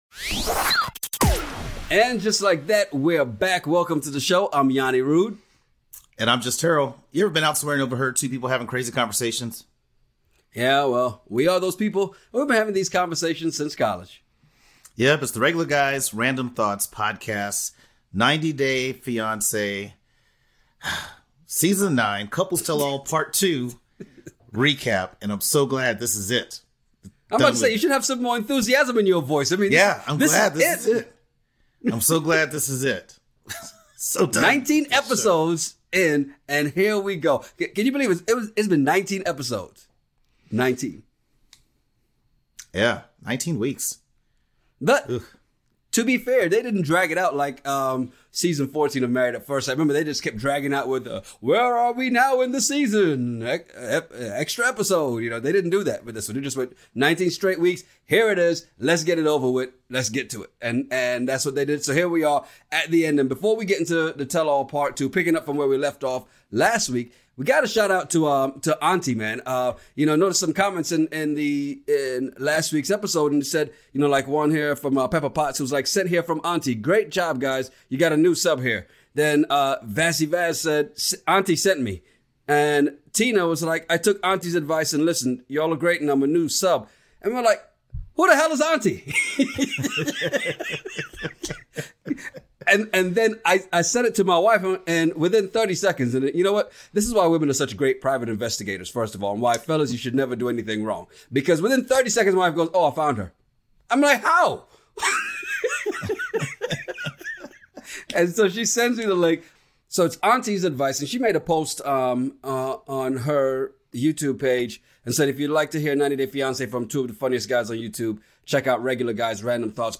It’s part 2 of the Tell-All and round 2 of Spaaah-kles vs Jaaawn! Plus who knew this would be the sneaky cheater of the season?. 2:39 Sparkles & John 8:57 Bilal & Shaeeda 13:42 Jibri & Miona 30:34 Emily & Kobe 39:25 Yve & Mohamed 51:09 Thoughts on Season 9 Ever been somewhere and overheard two guys having a crazy conversation over random topics?